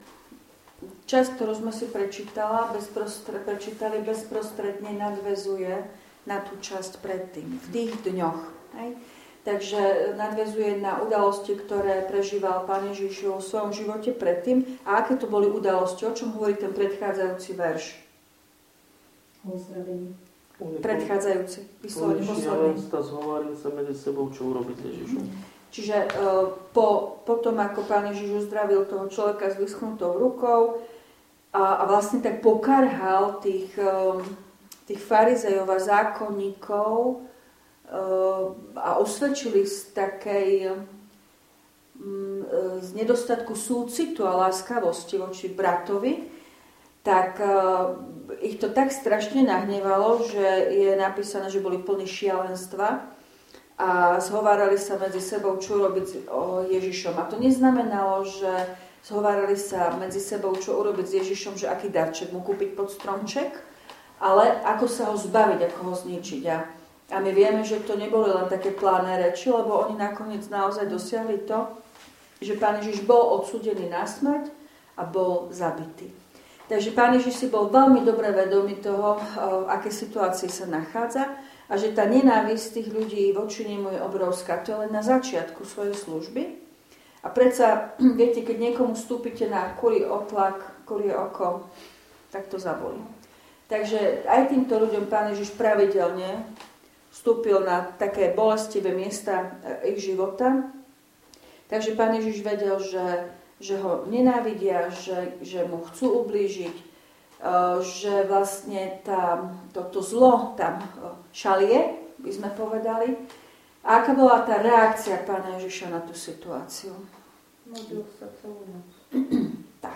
Biblická hodina zo dňa 27.11.2024
V nasledovnom článku si môžete vypočuť zvukový záznam z biblickej hodiny zo dňa 27.11.2024.